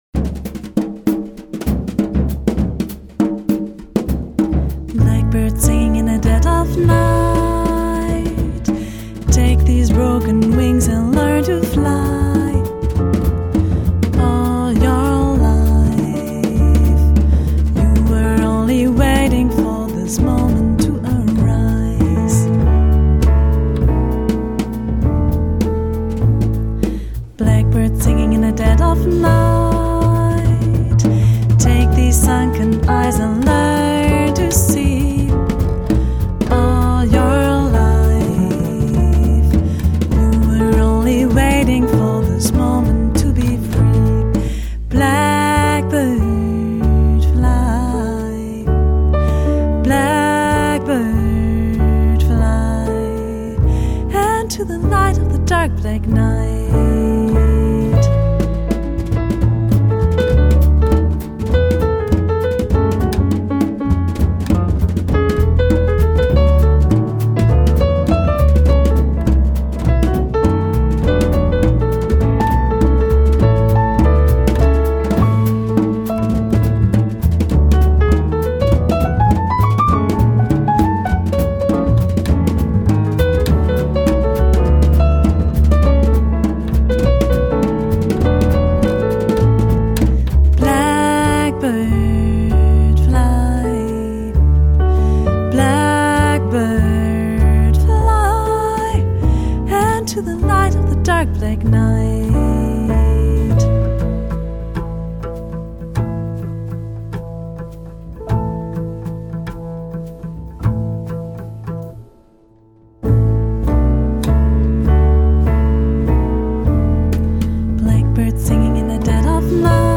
elegantes hauptstadt-quartett